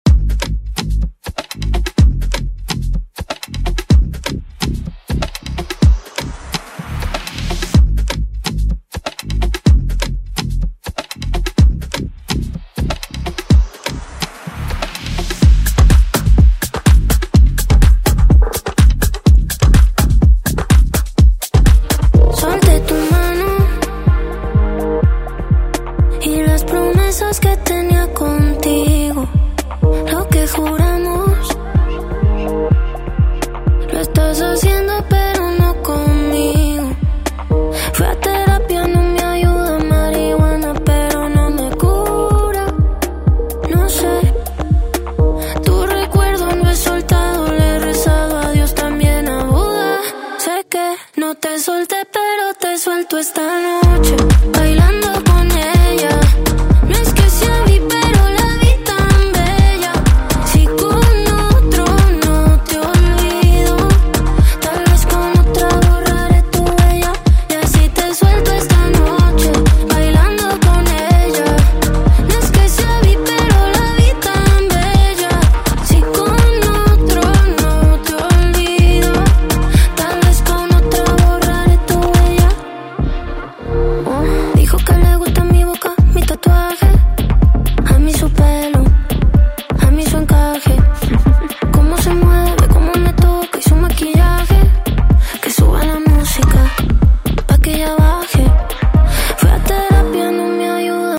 Genres: R & B , RE-DRUM , TOP40
Dirty BPM: 100 Time